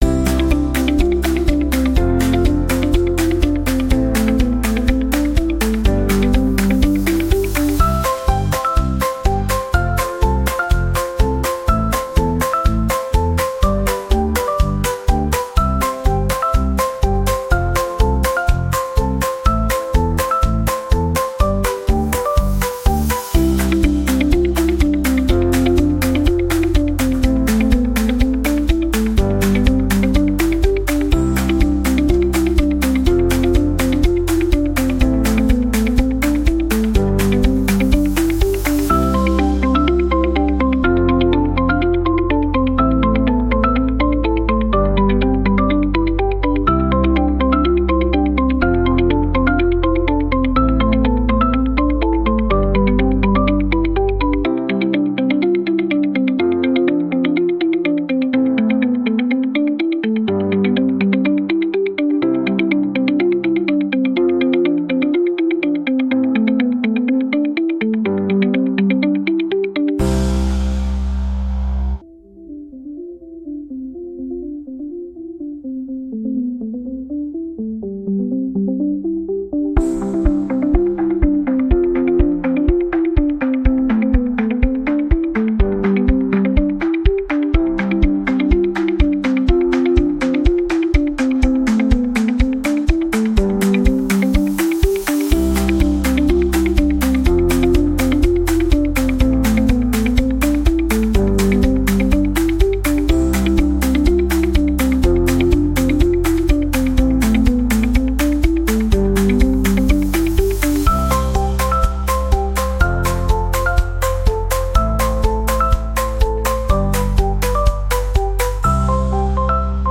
• Категория: Детские песни / Музыка детям 🎵